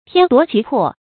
天奪其魄 注音： ㄊㄧㄢ ㄉㄨㄛˊ ㄑㄧˊ ㄆㄛˋ 讀音讀法： 意思解釋： 見「天奪之魄」。